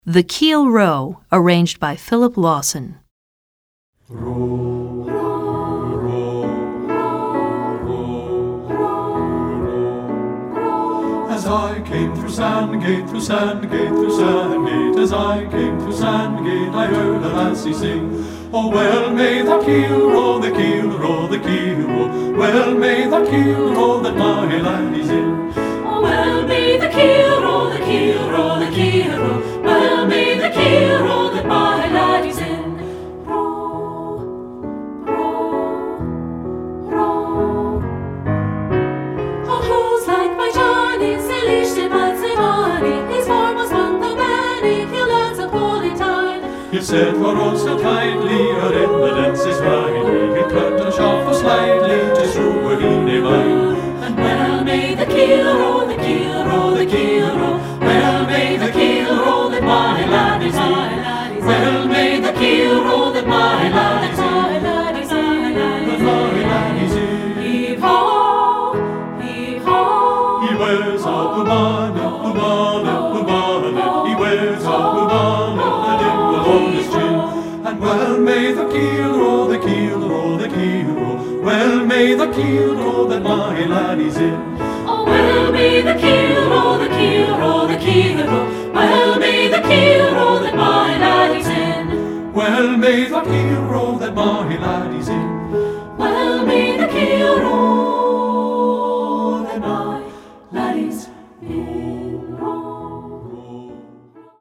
Choral Folk Multicultural
English Folk Song
SAB